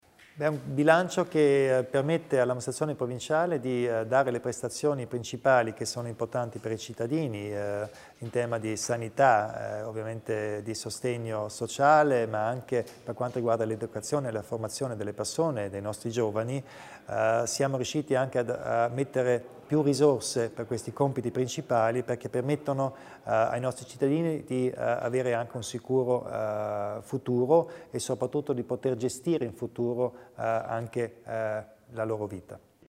Il Presidente Kompatscher spiega le priorità del bilancio di previsione 2018